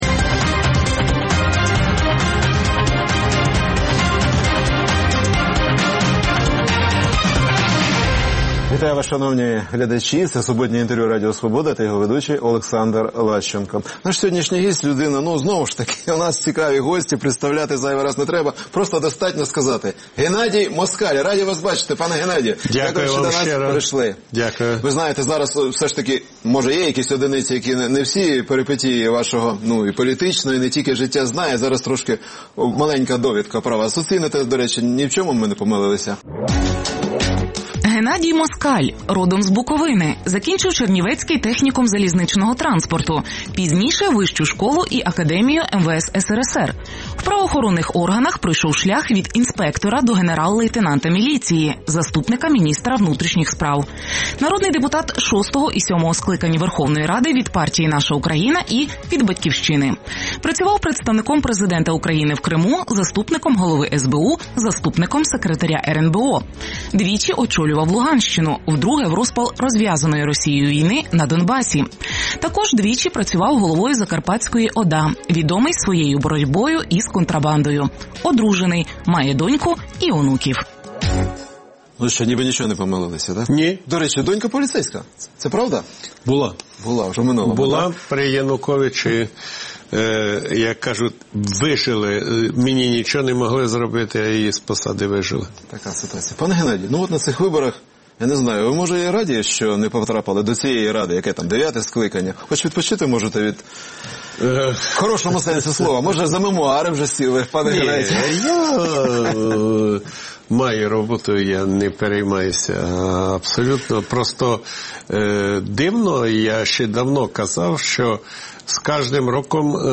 Суботнє інтерв’ю | Геннадій Москаль, політик